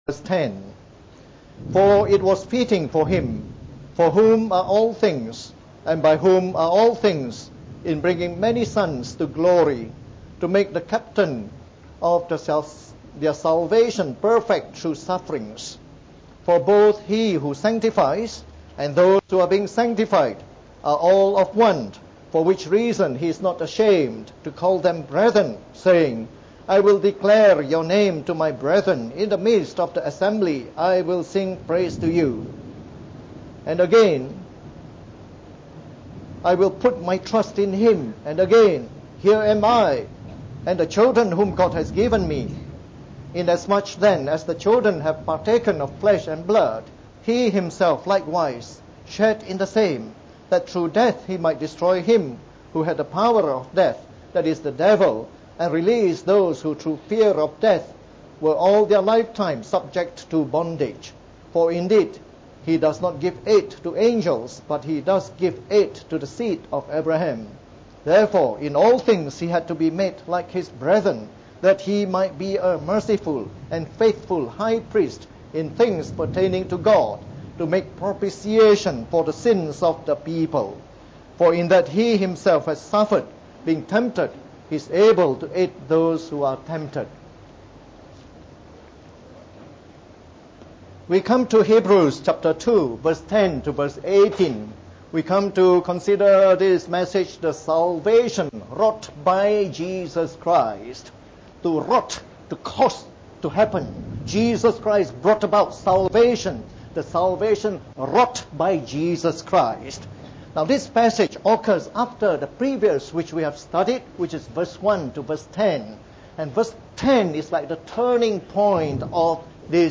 From our series on the “Epistle to the Hebrews” delivered in the Evening Service.